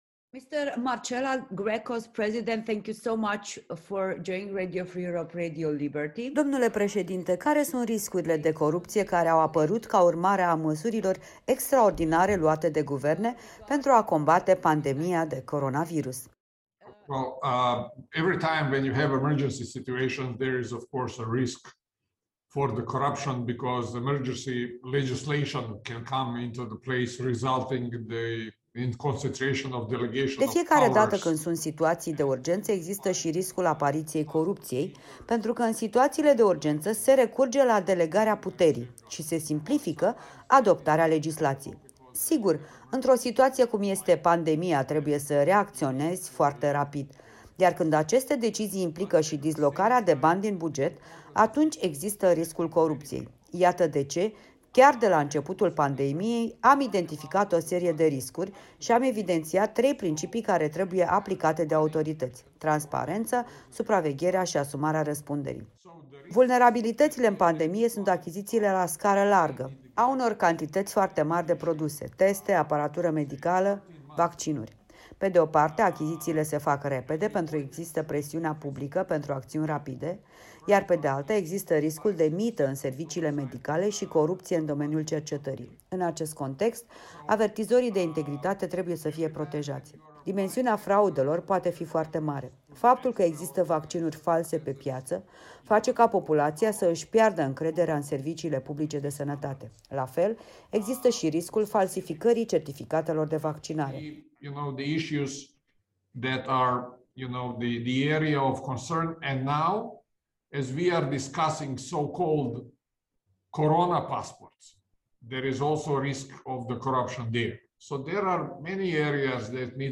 Achizițiile publice, în special și activitățile de lobby trebuie să fie mai tansparente și să se desfășoare în baza unor ghiduri de conduită, spune interviu cu Europa Liberă Marin Mrčela președintele GRECO